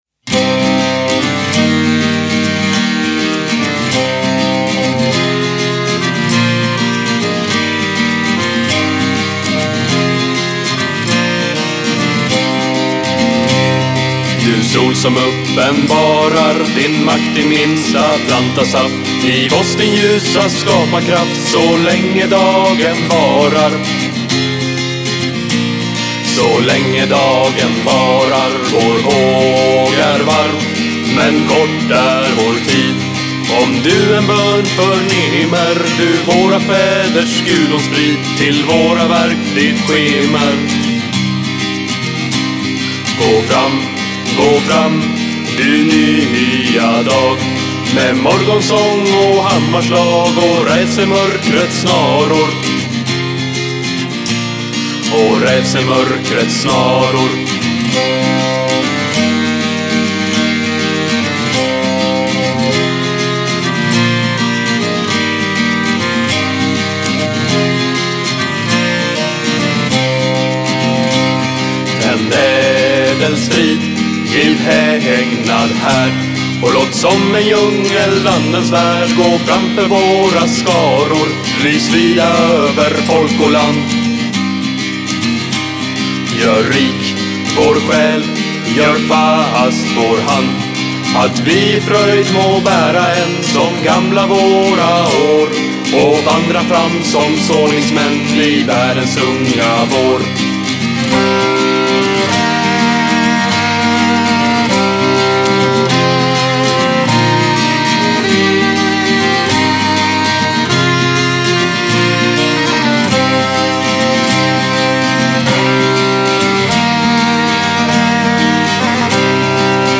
Style: Neofolk